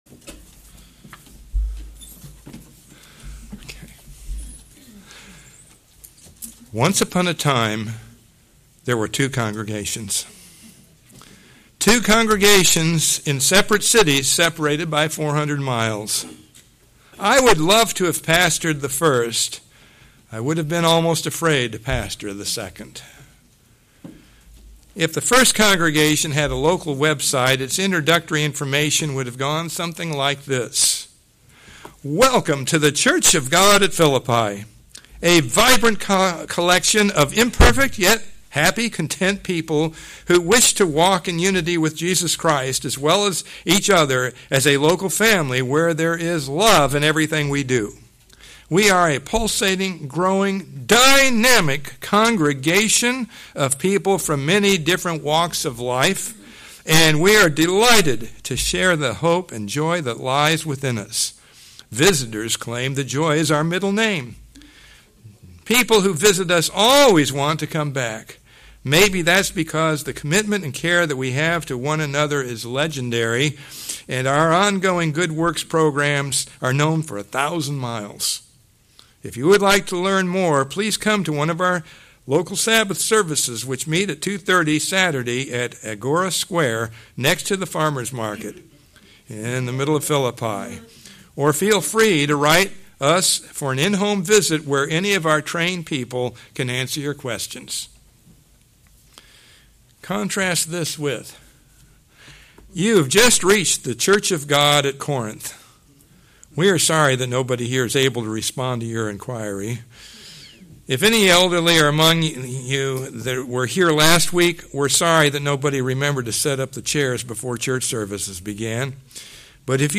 Given in Ft. Myers, FL